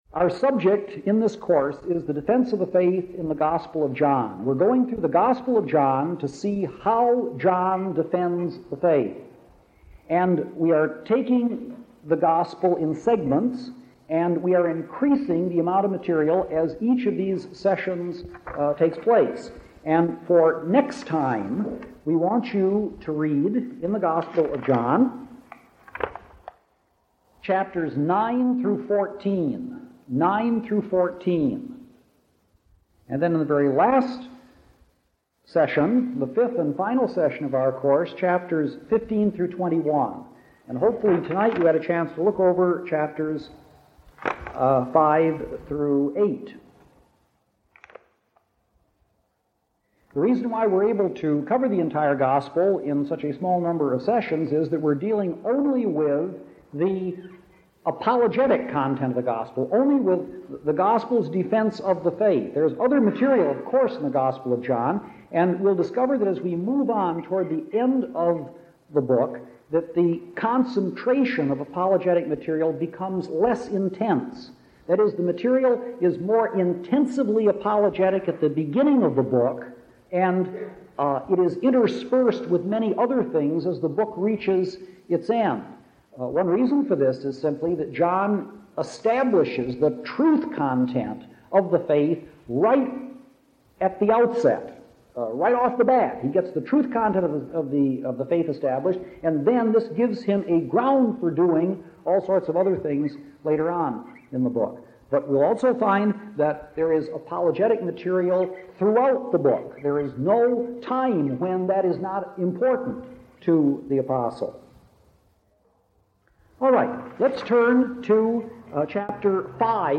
These popular lectures were delivered as a series to an enthusiastic audience in the late Walter R. Martin’s Southern California Bible Class.